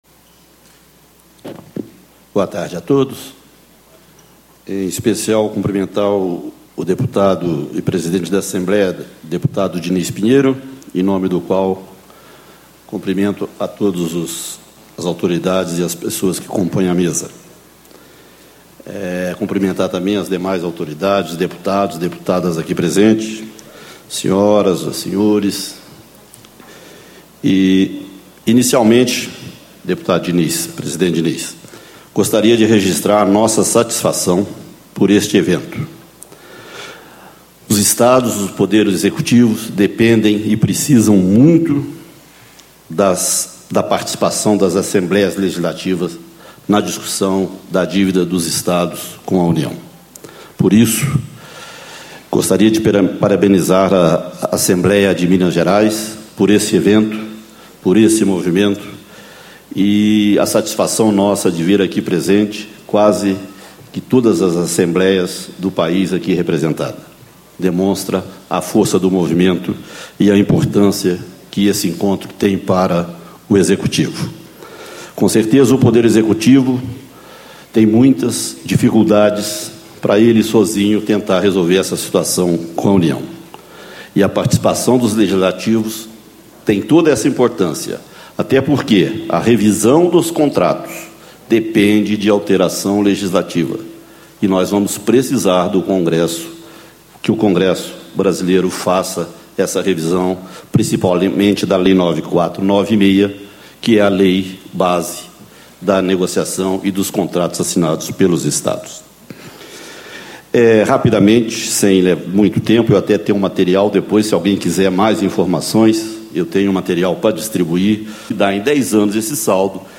Leonardo Colombini, Secretário de Estado da Fazenda de Minas Gerais
Discursos e Palestras A Renegociação da Dívida dos Estados com a União 13/02/2012 Escaneie o QR Code com o celular para conferir este audio Baixar áudio Leonardo Colombini, Secretário de Estado da Fazenda de Minas Gerais